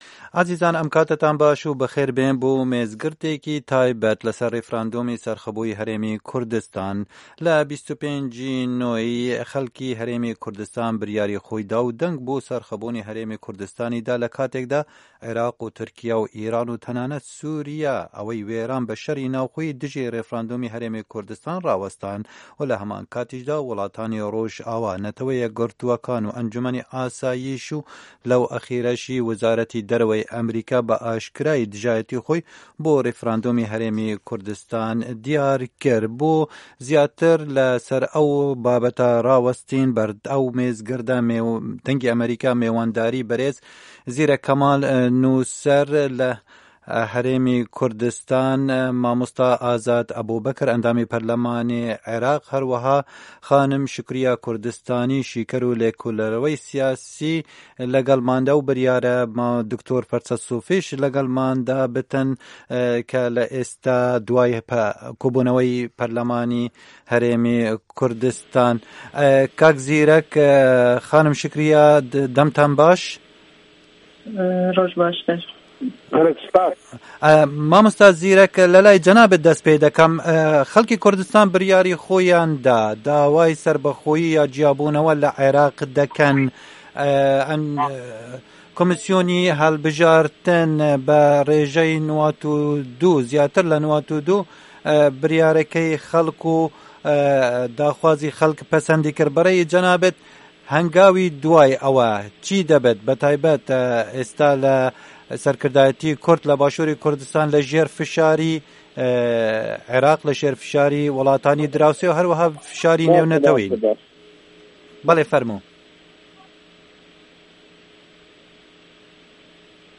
مێزگرد: ڕێفراندۆمی هه‌ریمی کوردستان